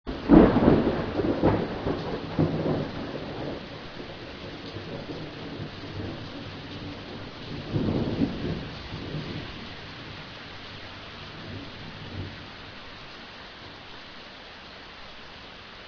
和大家分享一下暴雨素材
雷声.wav